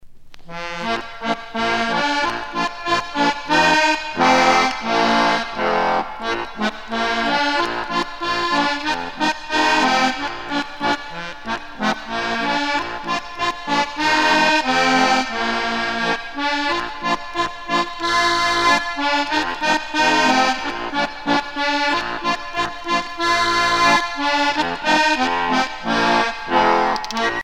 danse : polka